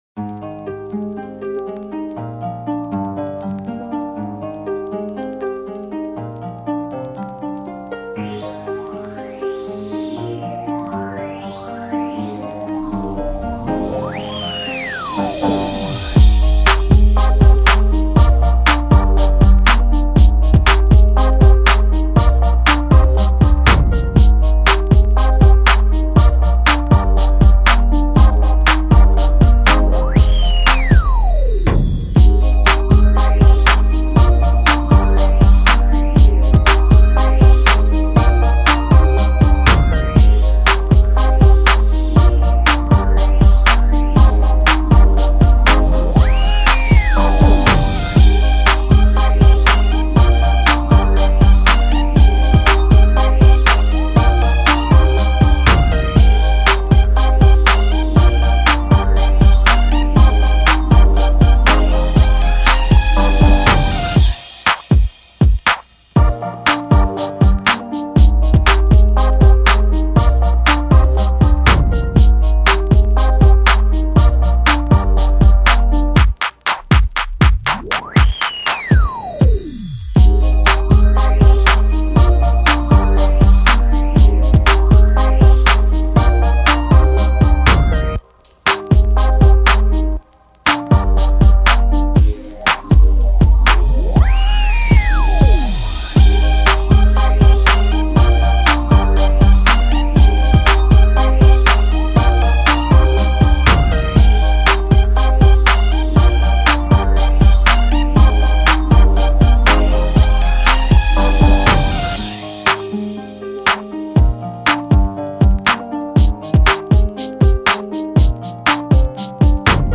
Rhythm and Blues Instrumentals